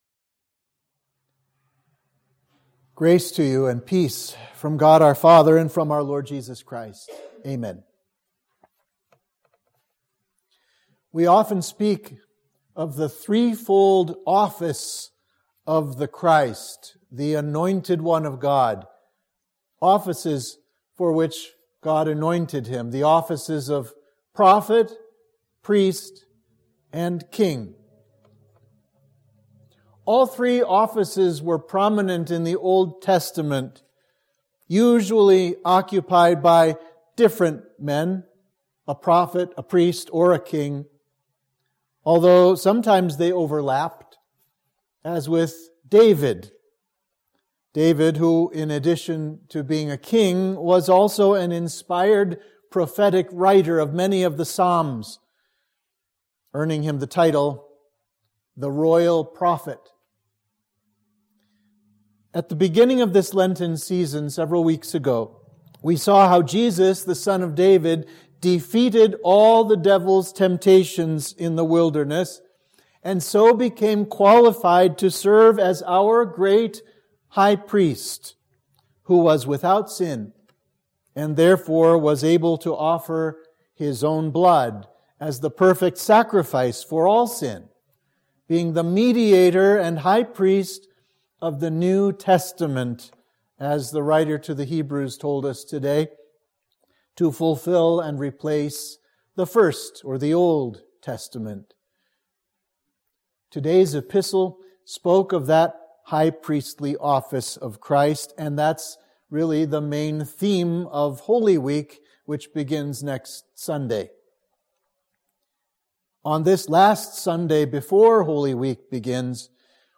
Sermon for Judica – Lent 5